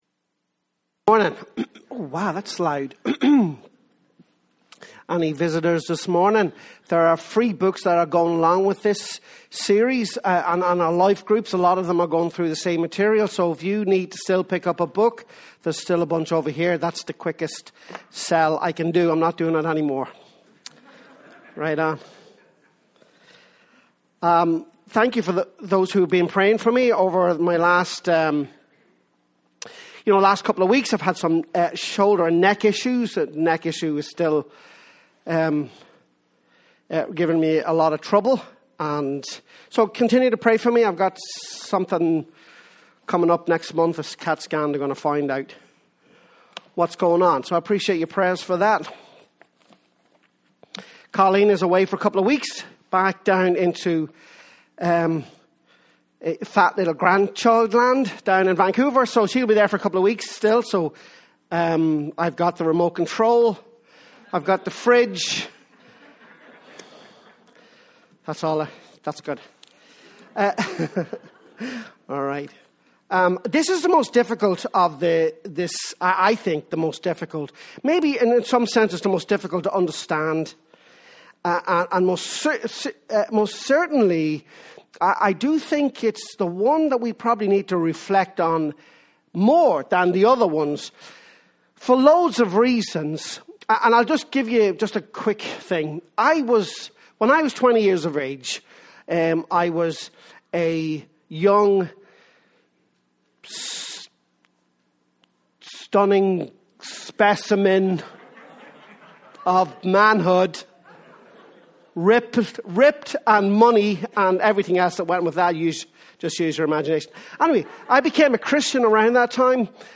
The Elder Brother – Calvary Baptist Church